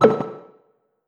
now-playing-pop-in.wav